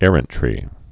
(ĕrən-trē)